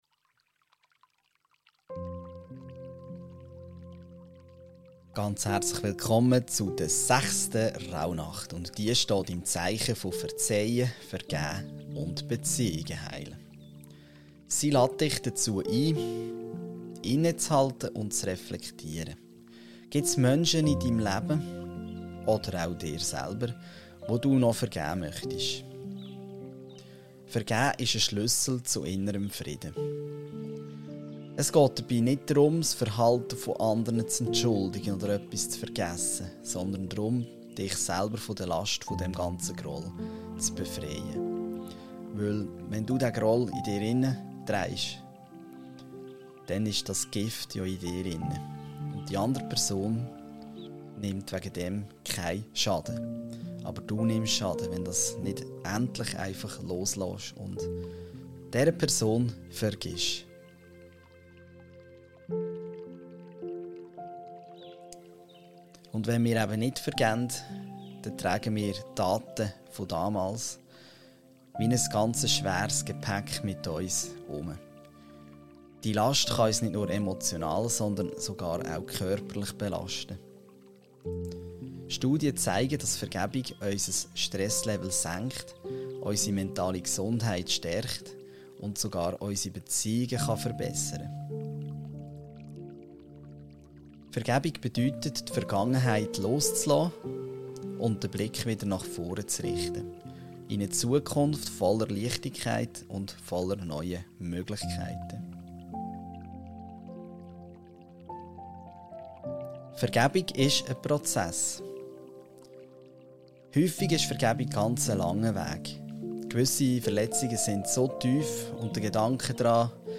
Am Ende der Episode leite ich dich durch eine sanfte Meditation, in der du einer Person begegnest, der du vergeben möchtest.